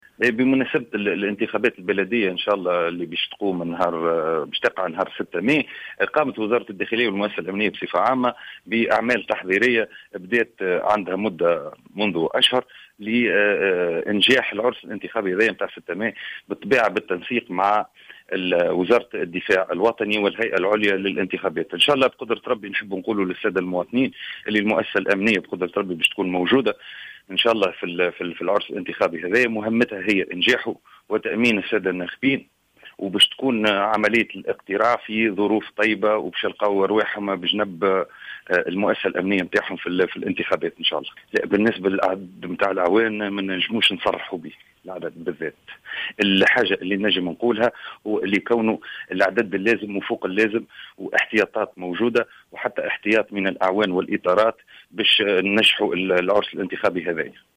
وأضاف في تصريح للجوهرة اف ام، أن وزارة الداخلية سخّرت الوحدات الأمنية اللازمة التي ستقوم بحماية جميع مراكز الاقتراع في جميع بلديات الجمهورية التونسية، مؤكدا أن الانتخابات البلدية ستجرى في ظروف طيبة.